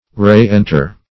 Reenter \Re*en"ter\, Re-enter \Re-en"ter\(r?*?n"t?r), v. t.